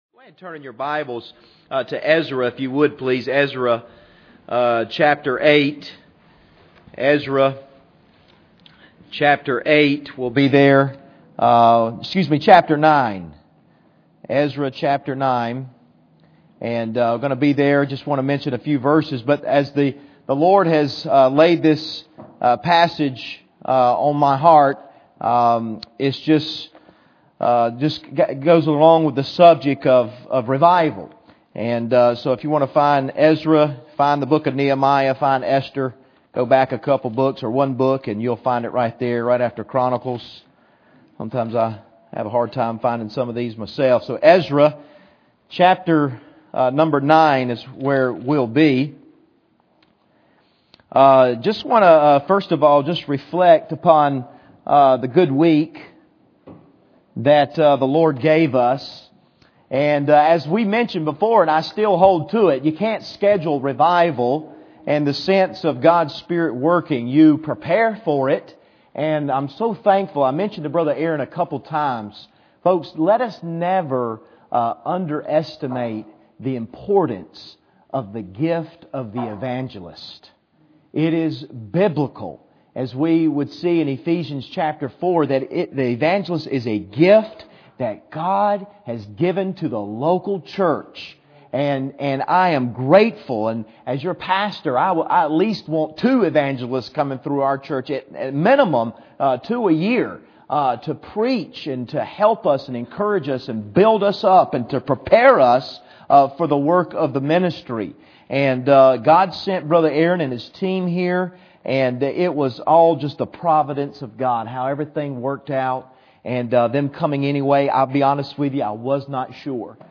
Revival Testimonies
Bible Text: Ezra 9:1-15 | Preacher: CCBC Members